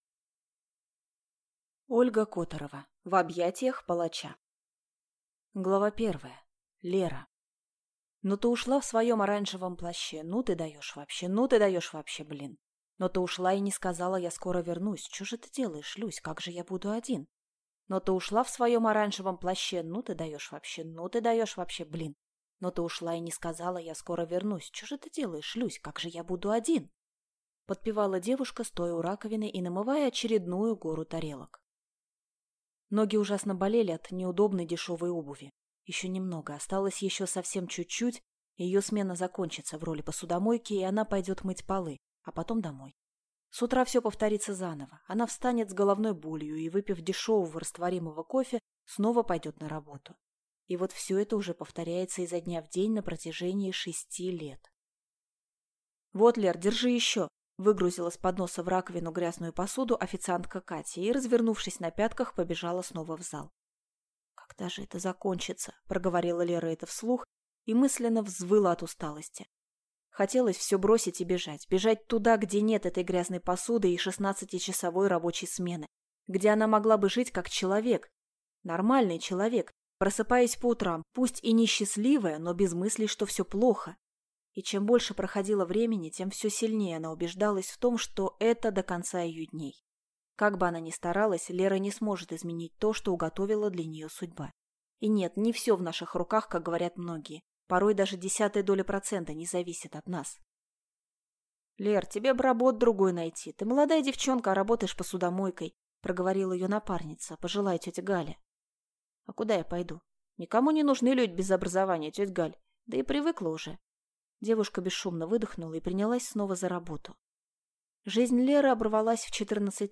Аудиокнига В объятиях палача | Библиотека аудиокниг